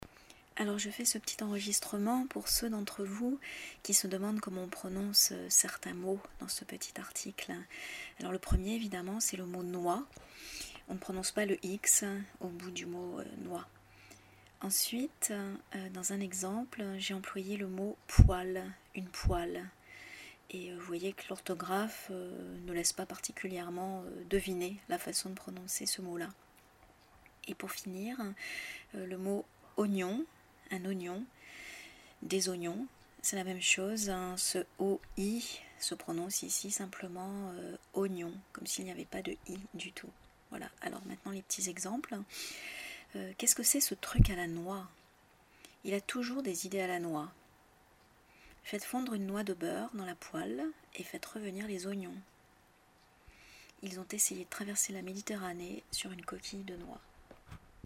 Pour écouter ces exemples et savoir prononcer poêle et oignon !